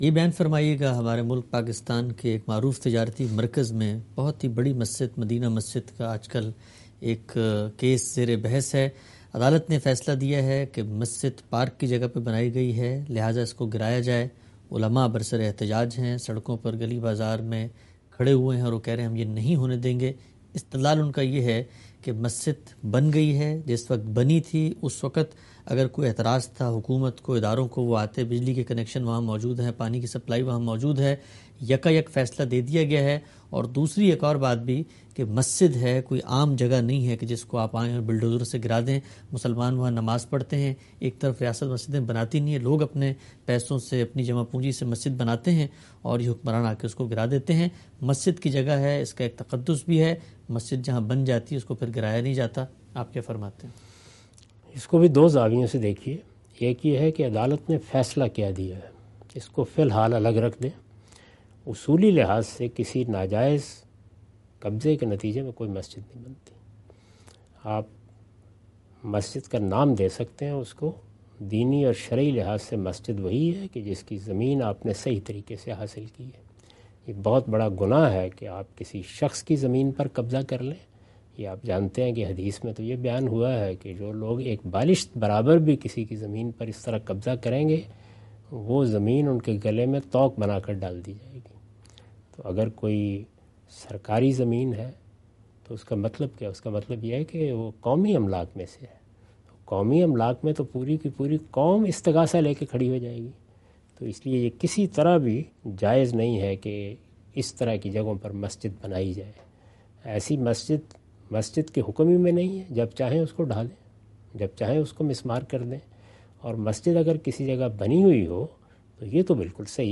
Category: Reflections / Questions_Answers /
In this video, Mr Ghamidi answer the question about "Can a mosque be demolished on a court order?".